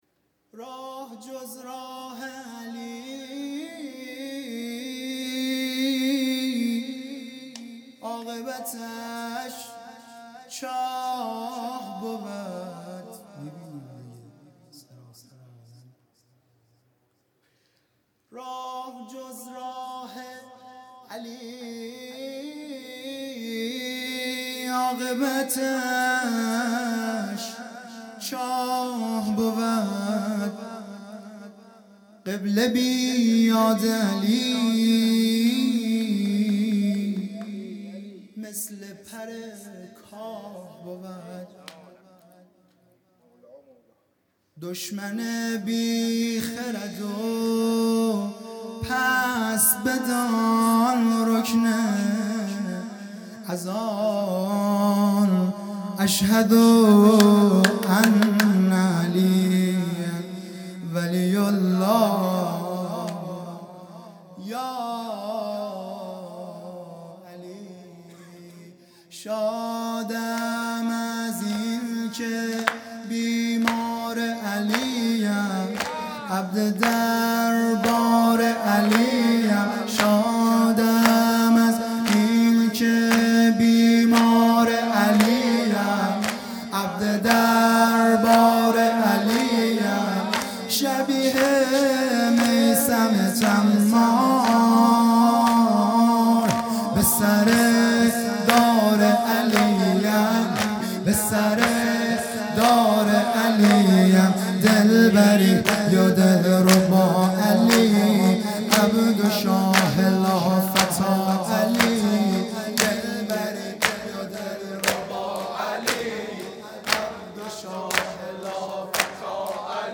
راه جز راه علی|جلسه هفتگی ۲۴ بهمن ۹۶